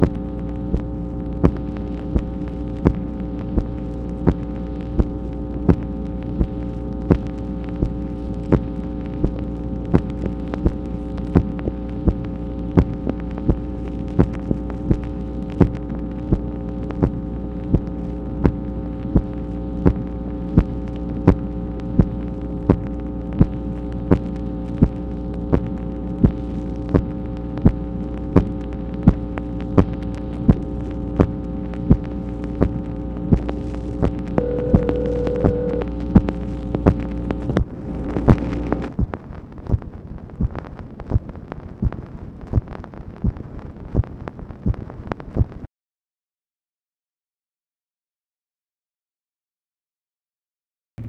MACHINE NOISE, October 1, 1966